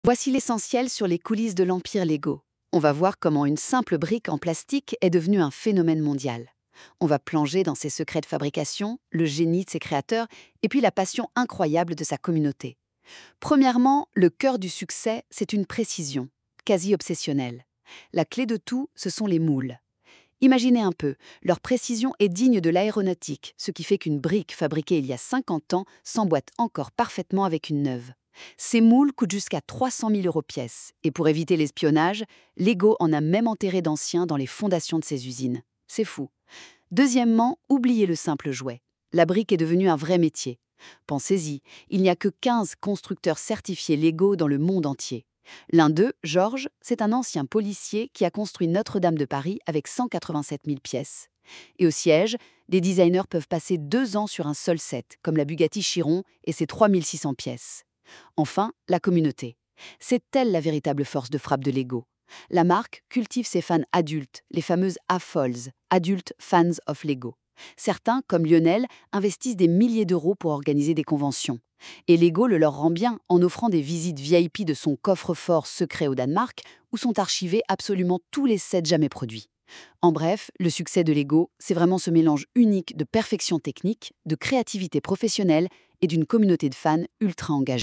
Ce reportage explore l’univers de Lego, leader mondial du jouet, en dévoilant les coulisses de sa réussite commerciale et technique.